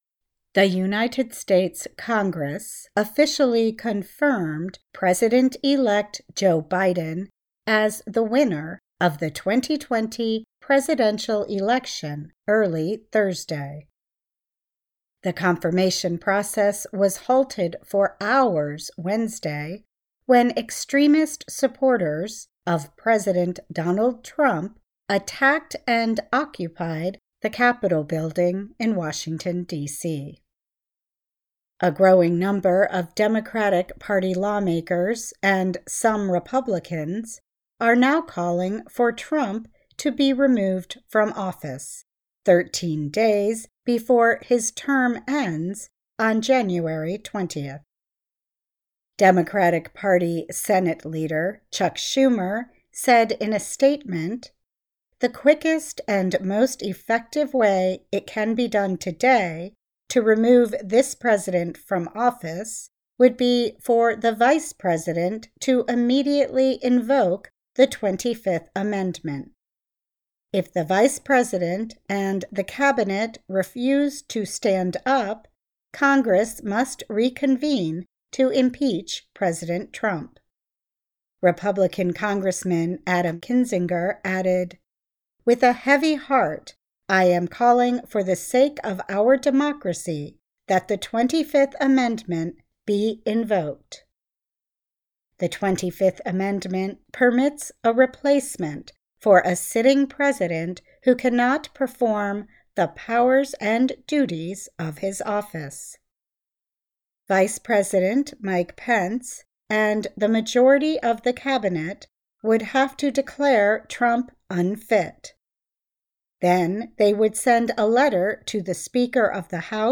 慢速英语:国会确认拜登胜选 各方呼吁将川普免职